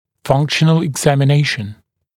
[‘fʌŋkʃənl ɪgˌzæmɪ’neɪʃn] [eg-][‘фанкшэнл игˌзэми’нэйшн] [эг-]функциональное обследование